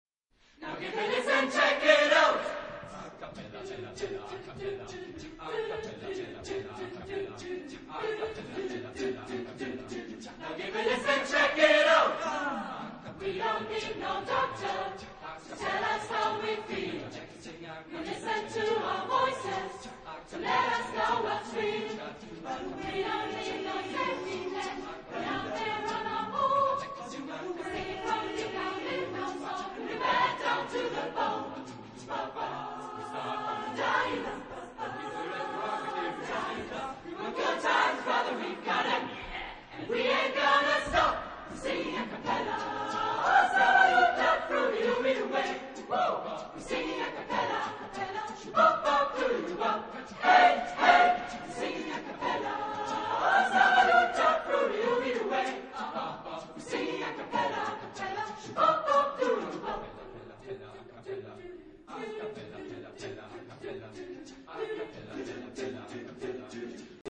Genre-Style-Forme : Funk ; Jazz vocal ; Variété ; Profane
Type de choeur : SATBB  (5 voix mixtes )
Solistes : Sopran (1) / Alt (1)  (2 soliste(s))
Tonalité : sol majeur